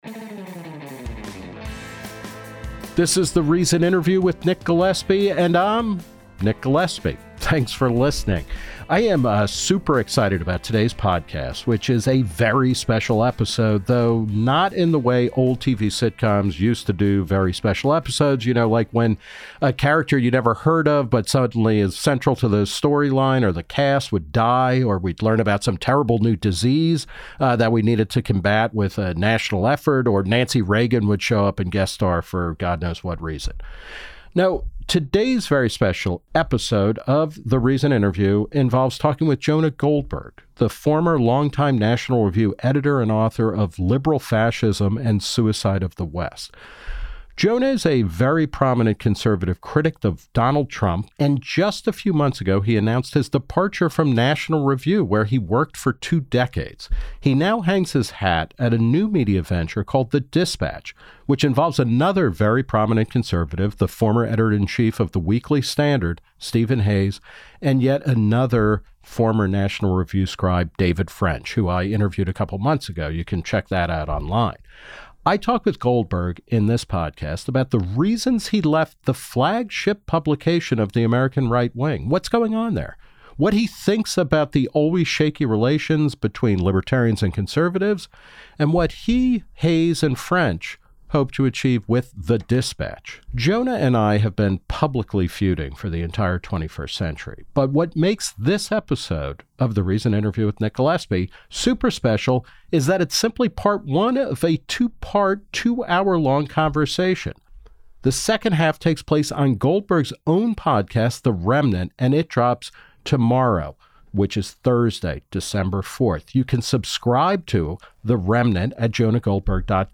No, The Reason Interview's very special episode is a two-part cross-over conversation with Jonah Goldberg, the former longtime National Review editor and bestselling author of Liberal Fascism and Suicide of the West.